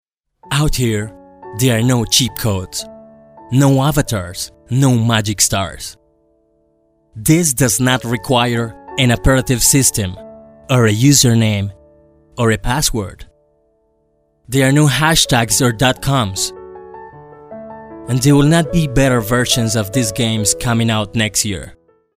Male
Spanish (Latin American), English (Latin American Accent)
Radio / TV Imaging
Internet Promo Eng Whit Accent